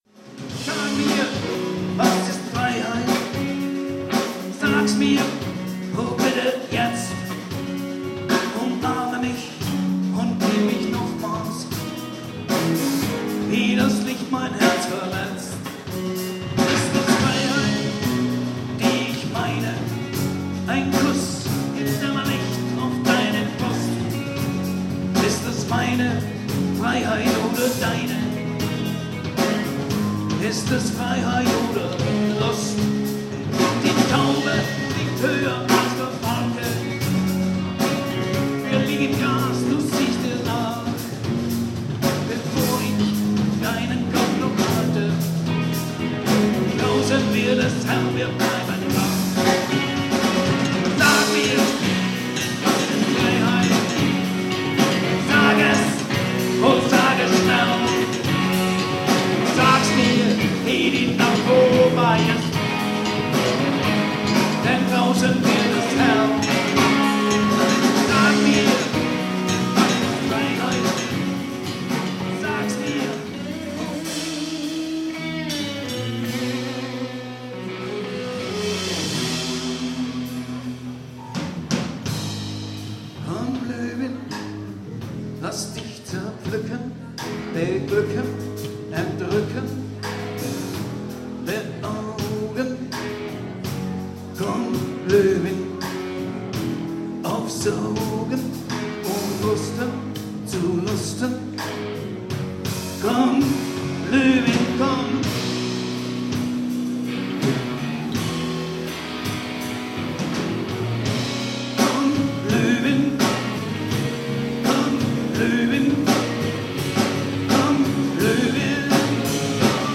"SCHOOL OF ROCK" - Rockkonzert an unserer Schule
Im Rahmen eines Maturaprojektes fand am 14.2.2013 im Theatersaal des Österreichischen St. Georgs-Kolleg ein gut besuchtes Konzert unter dem Titel "SCHOOL of ROCK" statt.